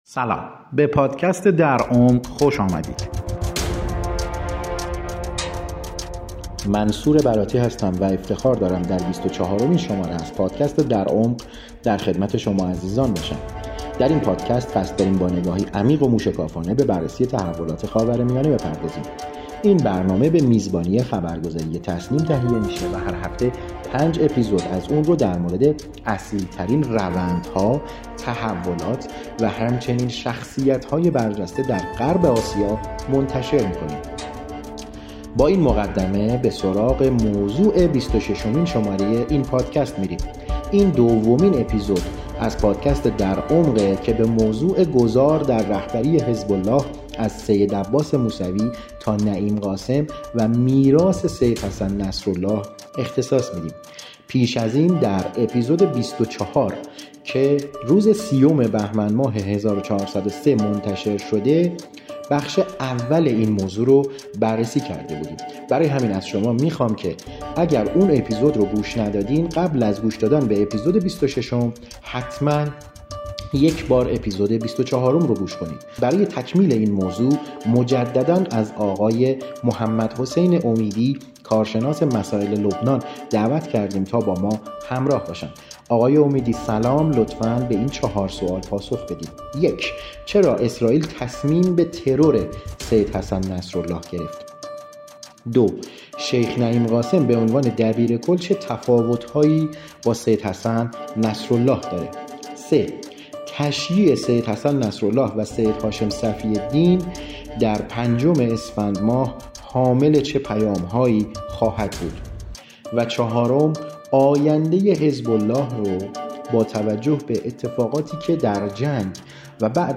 کارشناس مسائل لبنان است.